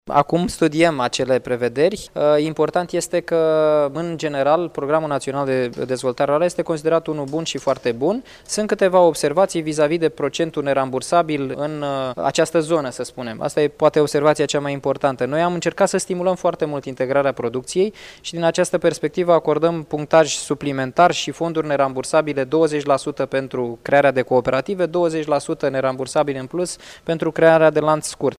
Reprezentanții Comisiei Europene au trimis o serie de observații Guvernului de la București referitoare la Planul Național de Dezvoltare Rurală din România, integrarea producției și înființarea asociațiilor și cooperativelor agricole, a anunţat, astăzi, la Iași de ministrul de resort Daniel Constantin, care a participat la conferința cu tema Fermele mici si asocierea.